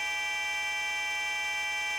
gyro.wav